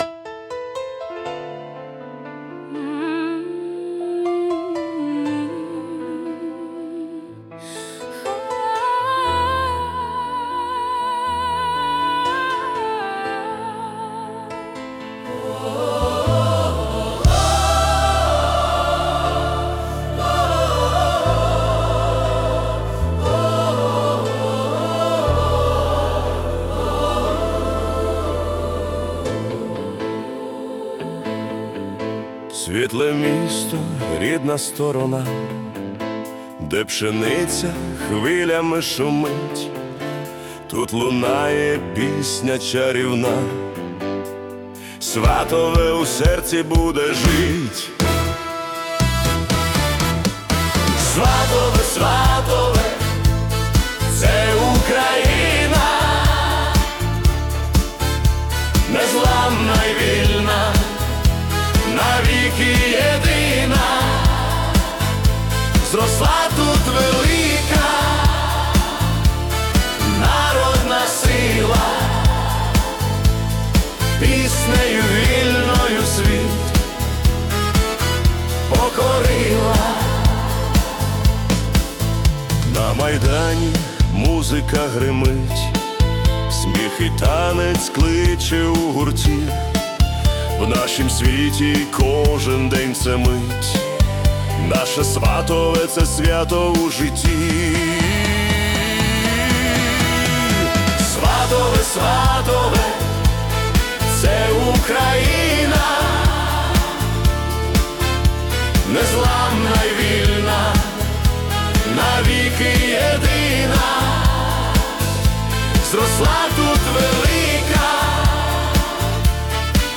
Patriotic Disco / Anthem